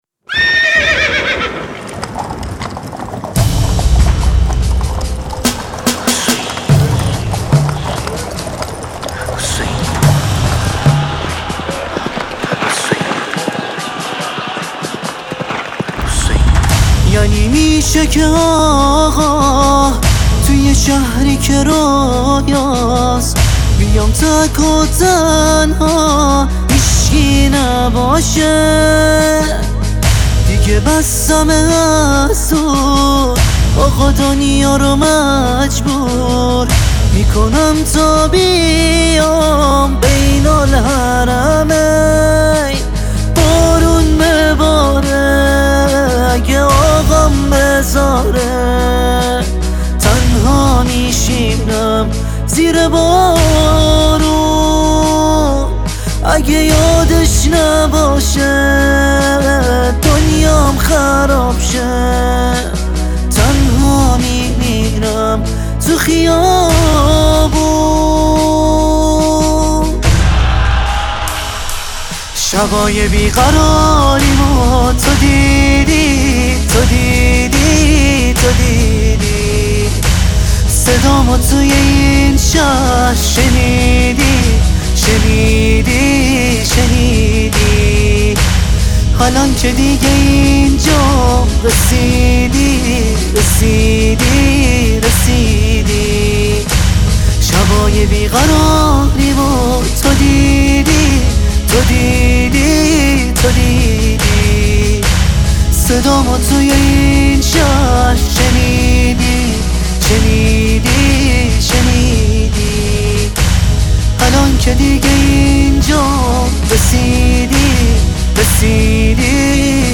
مذهبی و نوحه